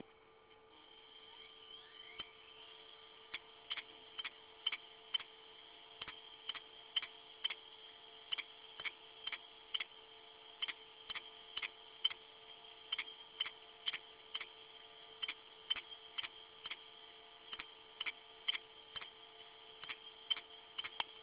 • Si sente un forte rumore di click all'accensione, questo è forse il caso peggiore del problema più comune di questa serie.
hard disk Western Digital rumoroso con diversi problemi di inizializzazione(.wav file, 34k). Dati da questi dischi sono spesso recuperabili - questo file audio proviene da un Western Digital WD400EB ed è un sintomo che si incontra molto di frequente.
western-digital-wd400eb-noisy.wav